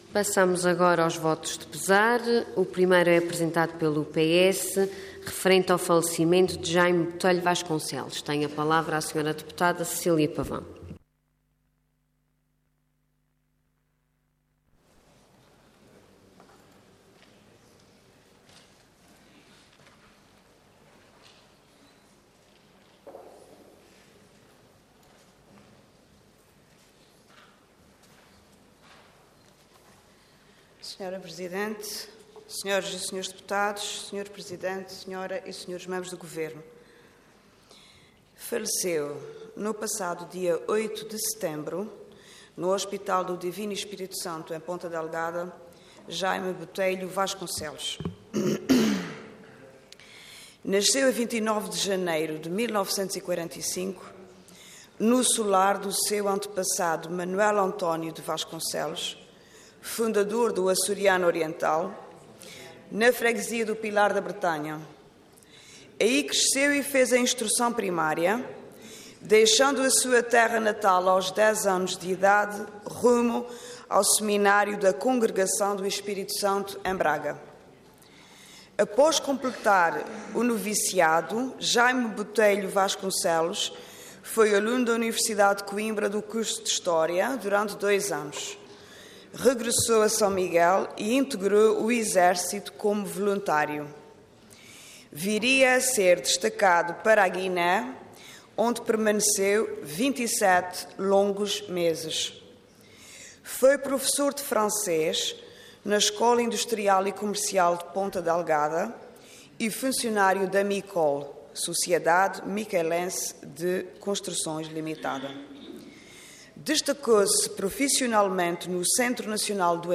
Detalhe de vídeo 13 de janeiro de 2016 Download áudio Download vídeo Processo X Legislatura Falecimento de Jaime Botelho Vasconcelos Intervenção Voto de Pesar Orador Cecília Pavão Cargo Deputada Entidade PS